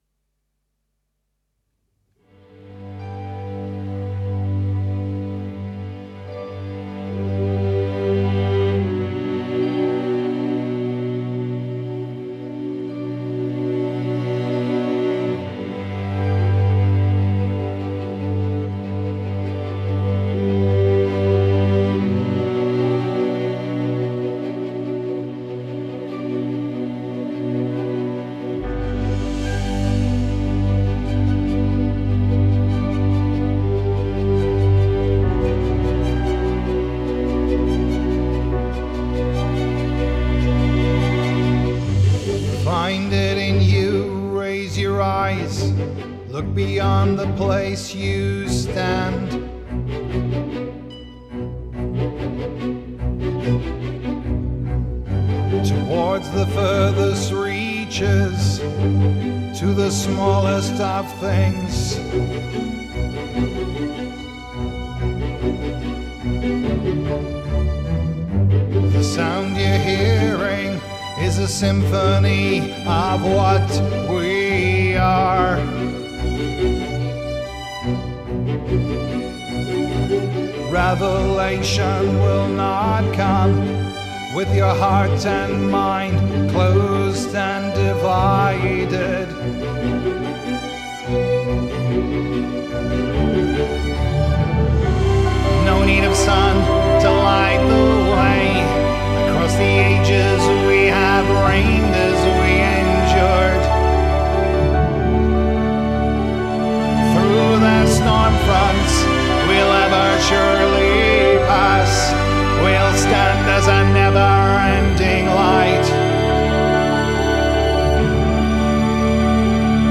arranged purely for voice and orchestra
Genre: Classical, Electronic